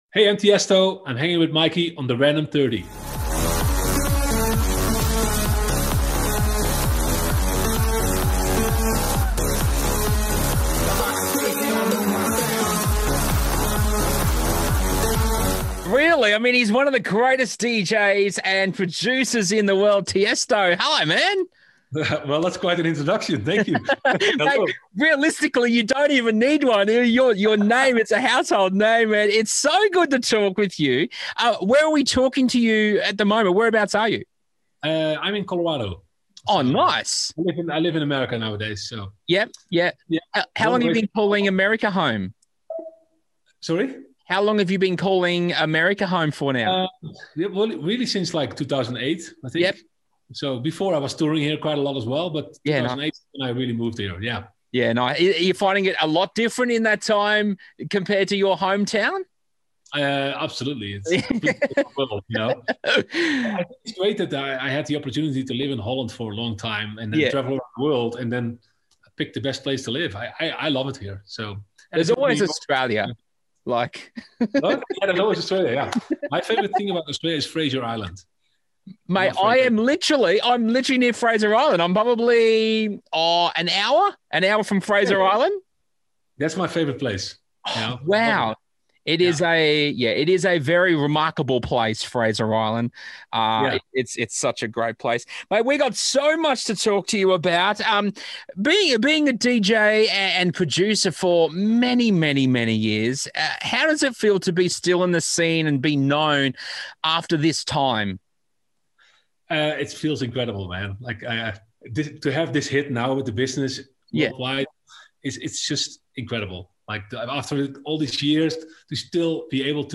Tiesto Interview | new single 'The Business' out now